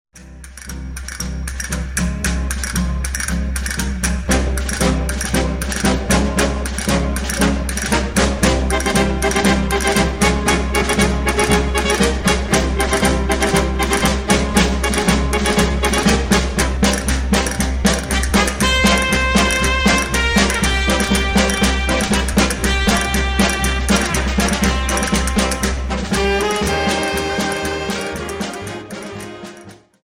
Dance: Paso Doble